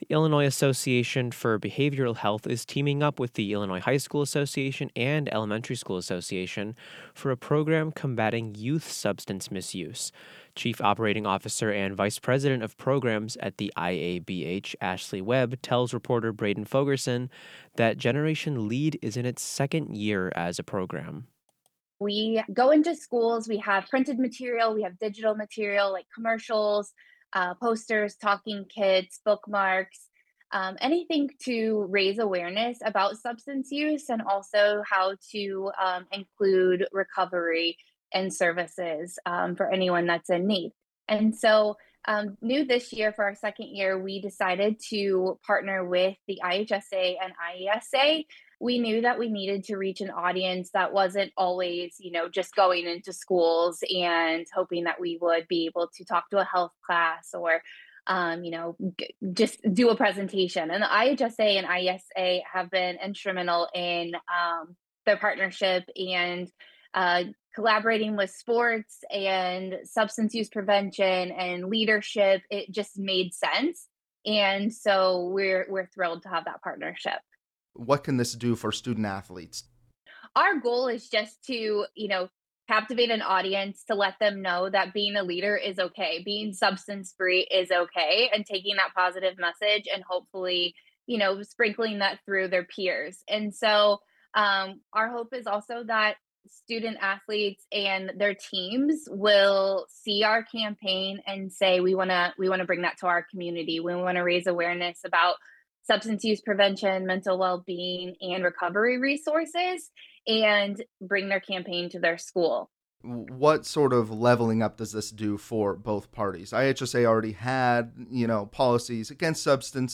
during an interview on WGLT’s Sound Ideas.